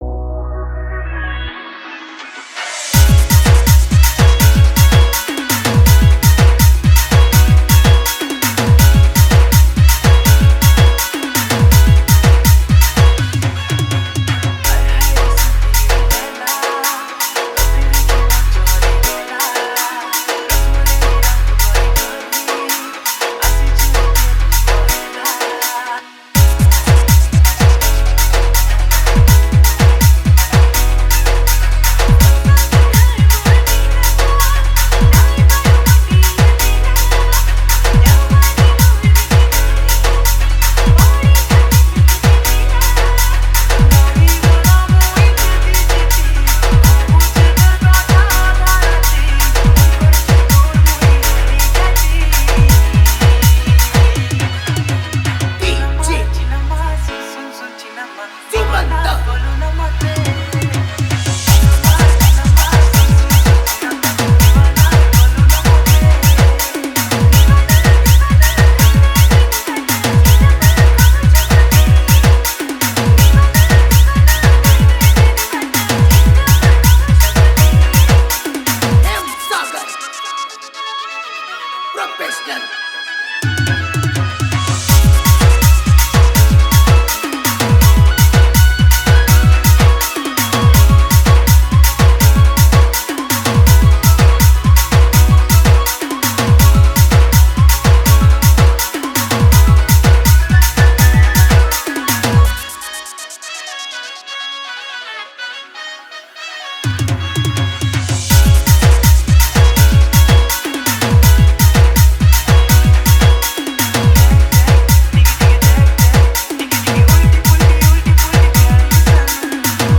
Sambalpuri Dj Song 2024